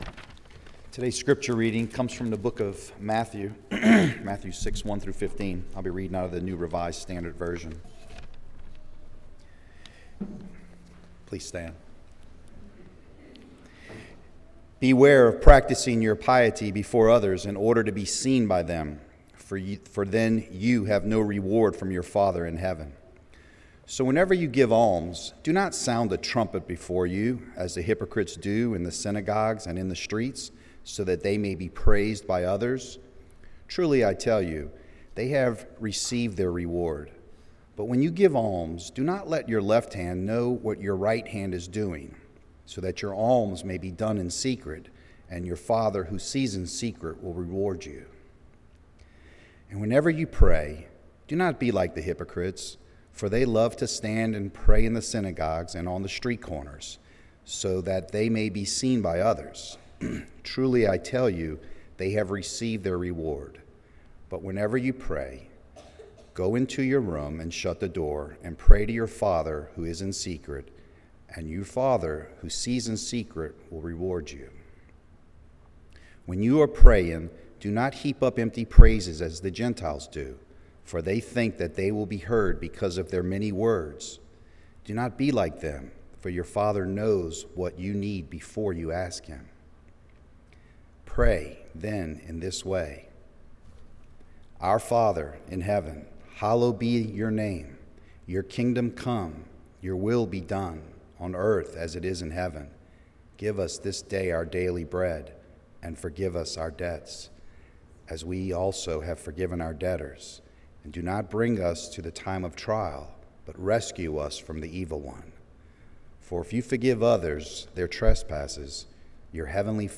St. Charles United Methodist Church Sermons